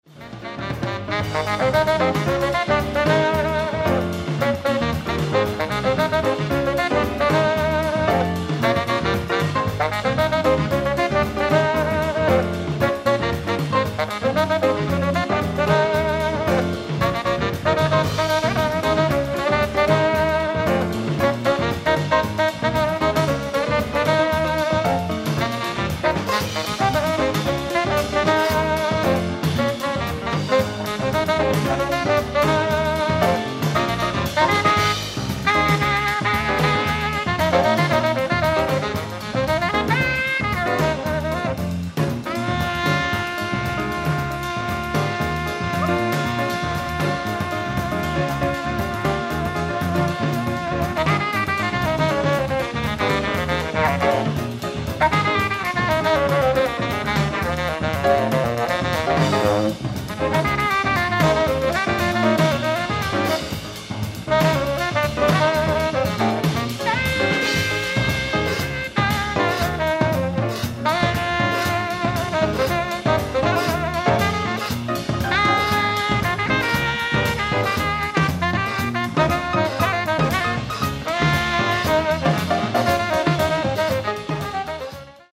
ライブ・アット・ポリ・ジャズフェスティバル、ポリ、フィンランド 07/13/1979
※試聴用に実際より音質を落としています。